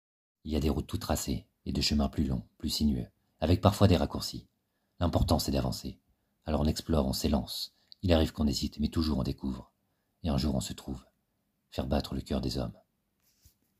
Voix
- Baryton-basse